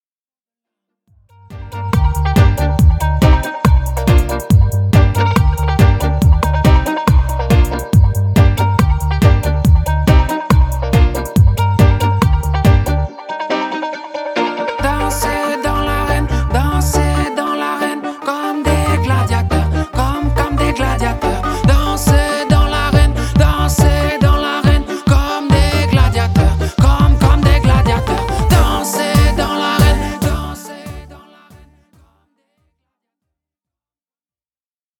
Le reggae reste néanmoins le fil conducteur de cet EP.